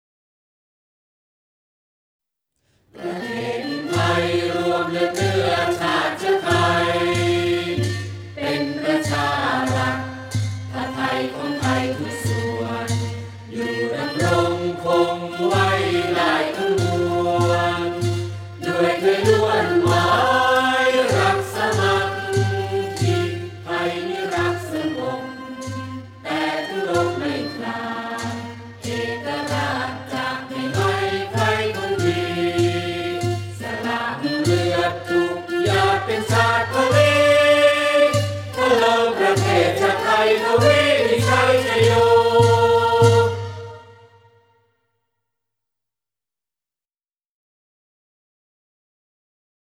เพลงชาติ (วงเครื่องสาย)